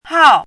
chinese-voice - 汉字语音库
hao4.mp3